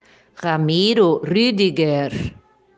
Isto porque as vogais U e E, quando juntas, tem o som de I. Outro ponto a ser considerado é que o GER no final é pronunciado como se tivesse um U depois do G.
Pronúncia: Parque Ramiro Rí-di-guer